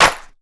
Clap (Day N Nite).wav